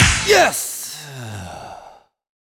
goodClap3.wav